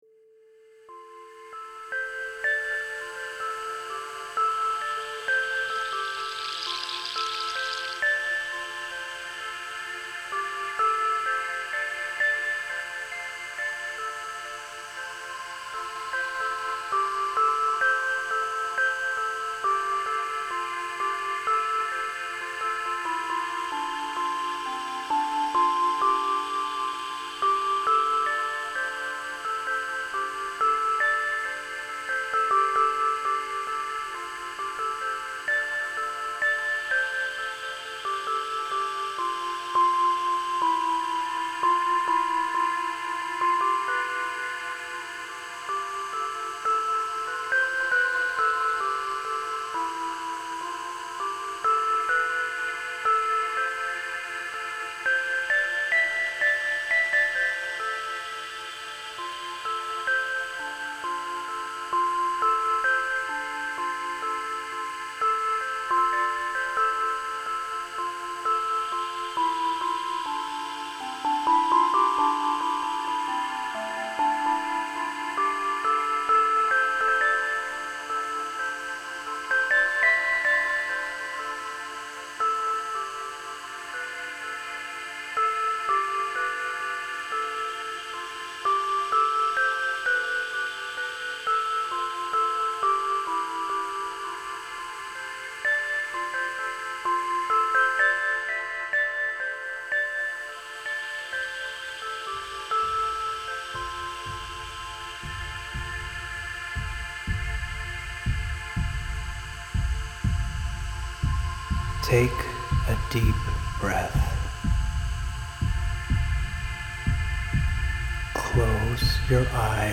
Follow-Your-Passion-Guided-Meditation.mp3